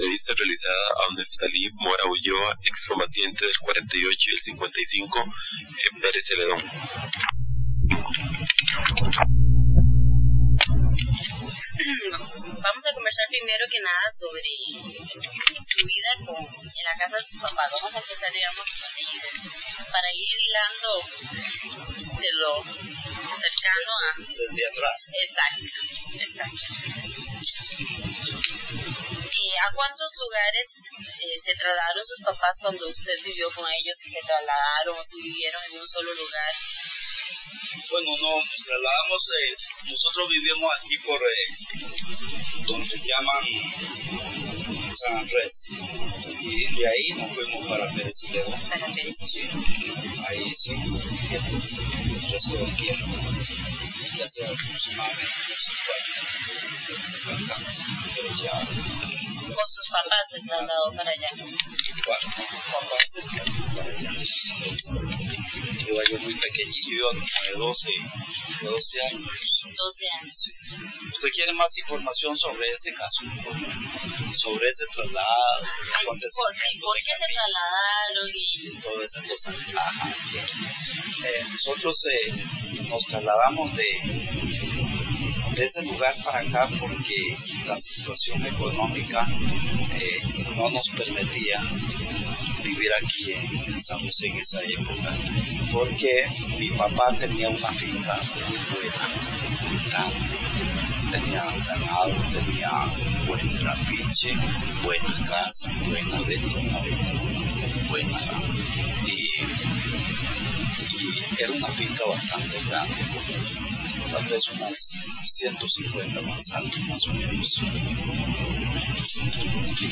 Entrevista
Notas: Casete de audio y digital